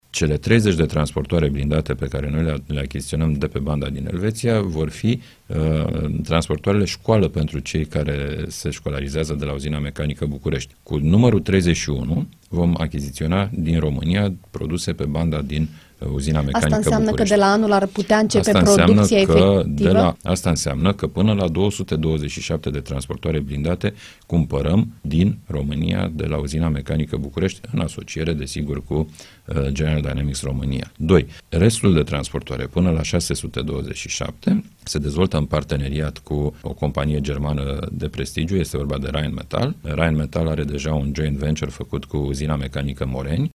Invitat la Interviurile Europa FM, ministrul Apărării, Mihai Fifor, a explicat că în ședința de mâine,  Guvernul va aproba proiectul de lege care permite această primă achiziție, în schimbul sumei de aproape 765 de milioane de dolari.